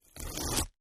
ho_fleshtear_05_hpx
Various parts of human flesh being ripped and torn. Mutilation, Body Dismemberment, Gore Tear, Flesh